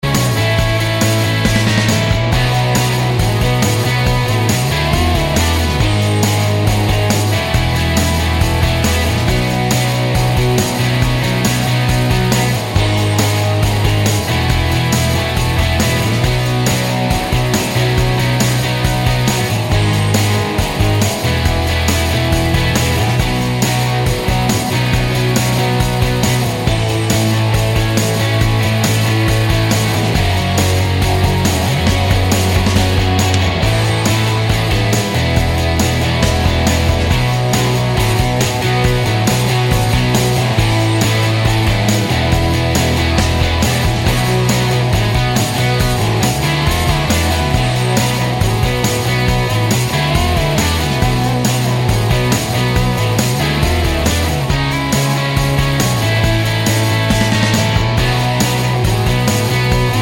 no Backing Vocals Indie / Alternative 5:12 Buy £1.50